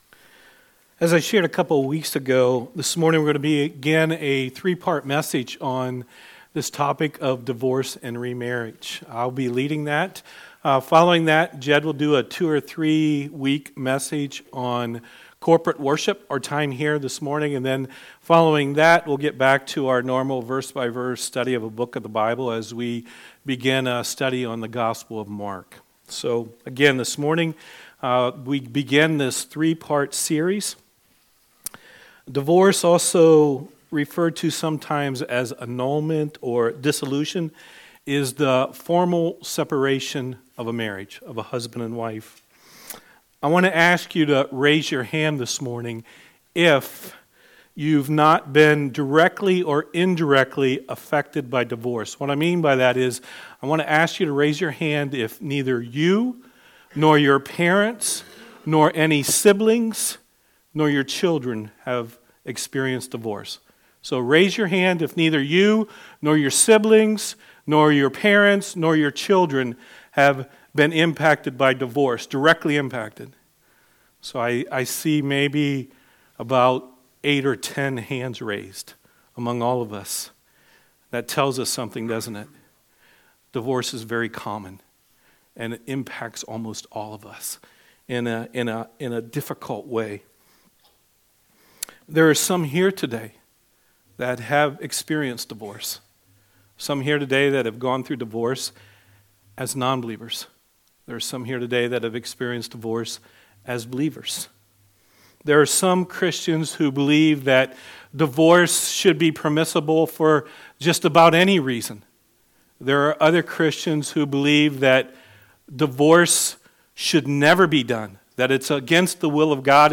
There were some recording issues during the first portion of this message. It picks up a few minutes into the sermon.